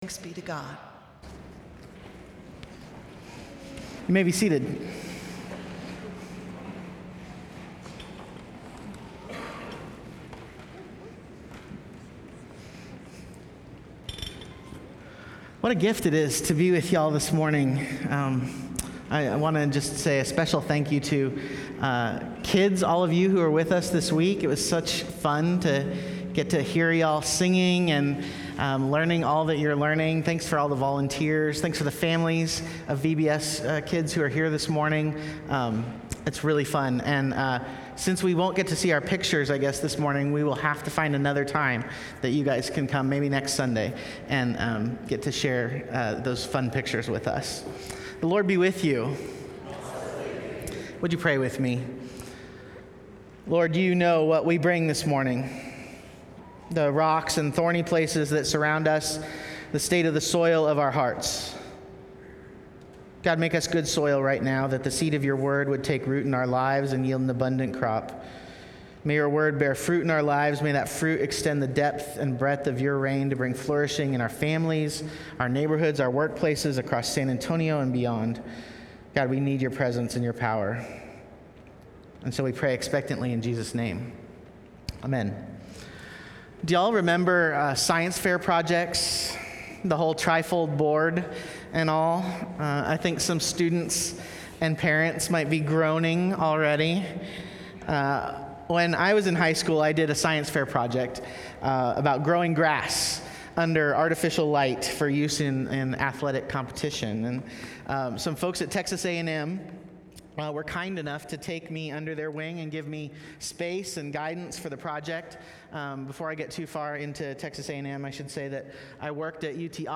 Travis Park United Methodist Church Sermons